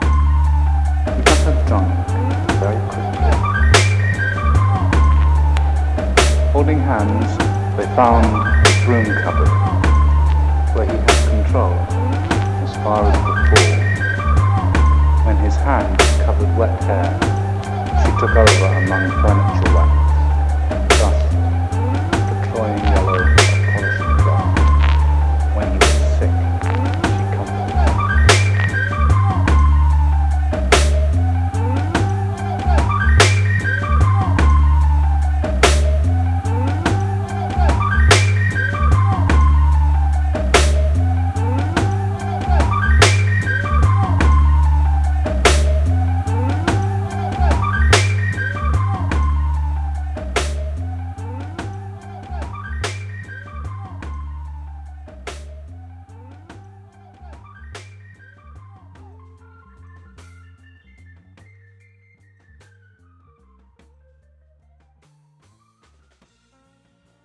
beat tape